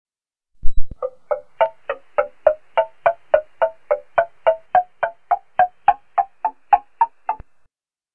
temple blocks.mp3